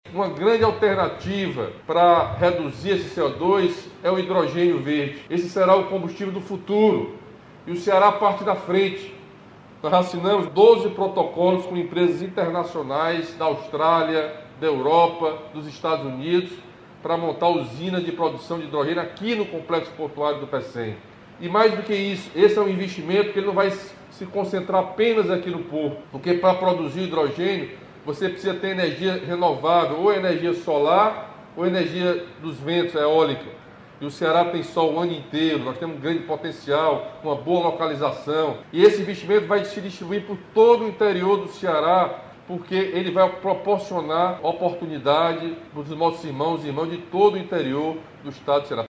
O Complexo do Pecém tem se destacado por atrair novos negócios comprometidos com a sustentabilidade. Camilo Santana pontuou um novo segmento que vai contribuir bastante com isso, além da geração de emprego e renda, como o HUB de Hidrogênio Verde.